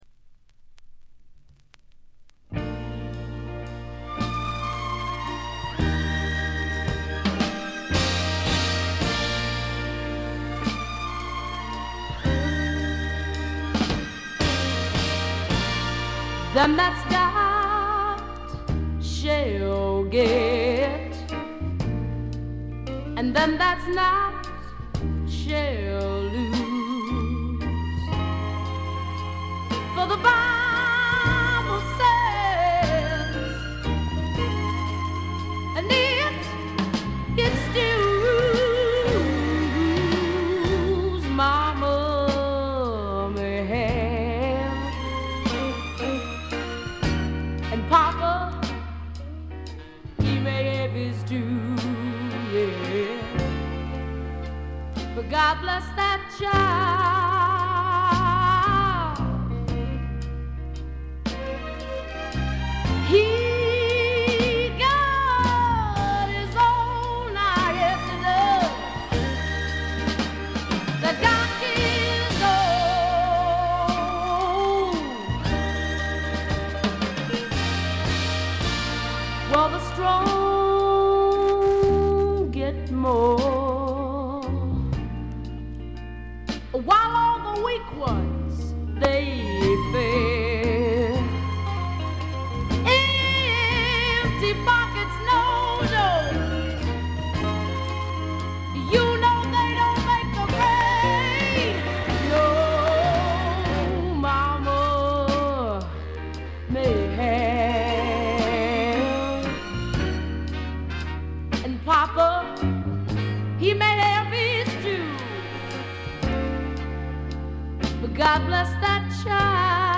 チリプチやや多め大きめ(特にA1)。鑑賞を妨げるほどのノイズはありません。
試聴曲は現品からの取り込み音源です。